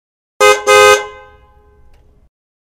Suara klakson Bus pendek
Kategori: Suara bel berbunyi
Keterangan: Suara klakson bus yang pendek dan khas ini cocok buat kamu yang ingin nada dering simpel tapi tetap catchy.
suara-klakson-bus-pendek-id-www_tiengdong_com.mp3